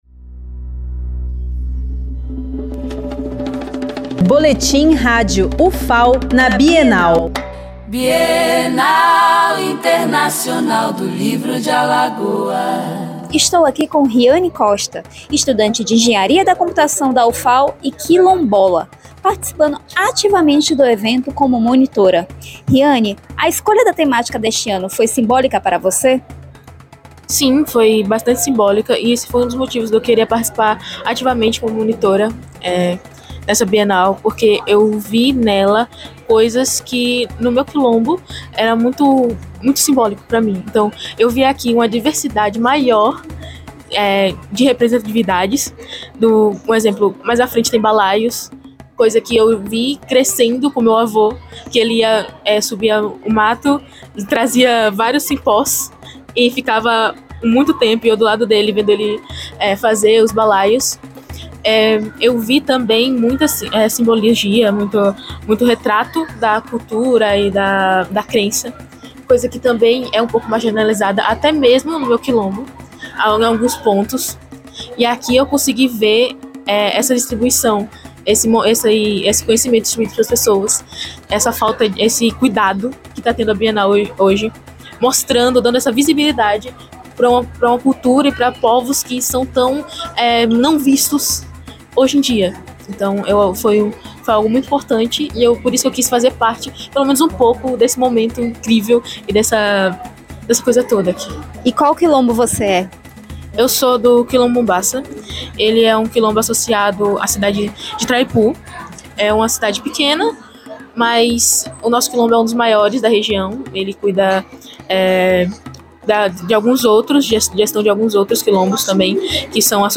Flashes com informações da 11ª Bienal Internacional do Livro de Alagoas, realizada de 31 de outubro a 9 de novembro de 2025